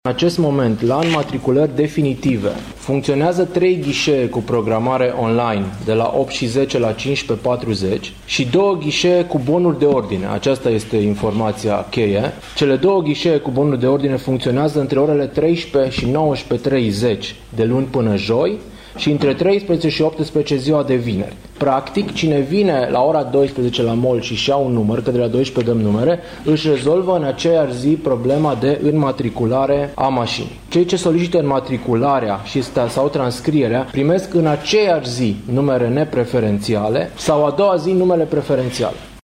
În plus, numerele nepreferențiale pot fie liberate pe loc, după reorganizarea completă a serviciului. Prefectul Mihai Ritivoiu.